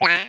monster_open.mp3